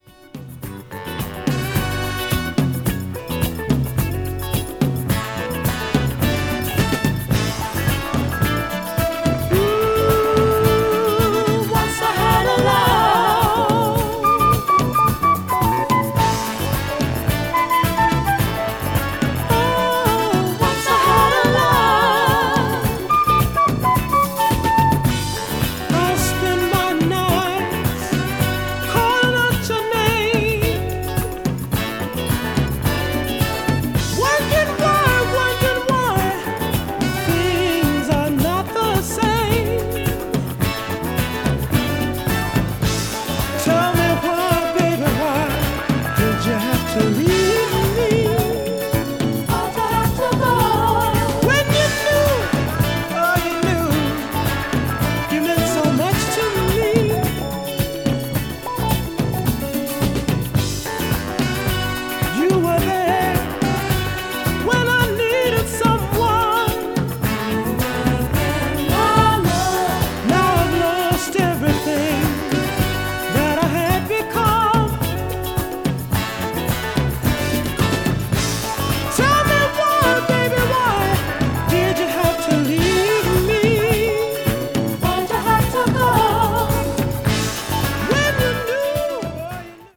media : EX/EX(some slightly noises.)
flutist
boogie disco   disco classic   funk   soul